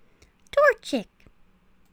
cries
TORCHIC.wav